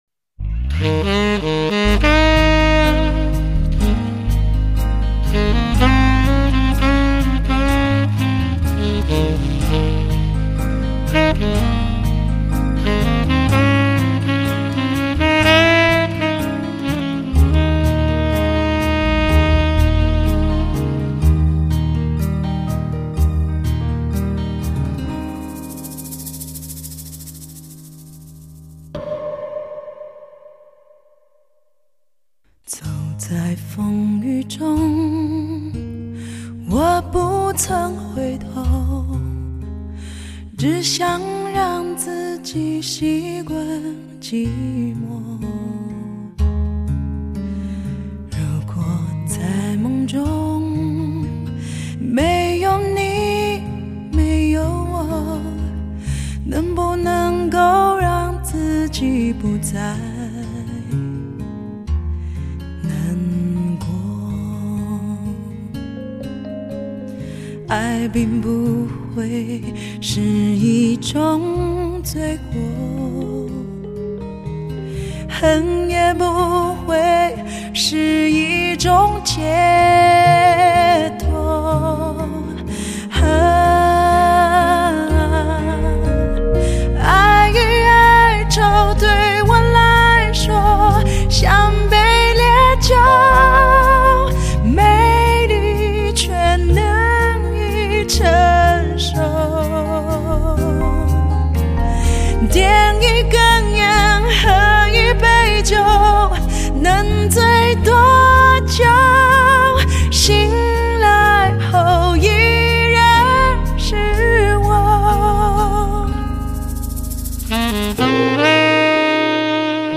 类型: 天籁人声
她的歌声充满情感暴发力与婉约的内敛
细致音色迸发出活力能量，让人轻松愉悦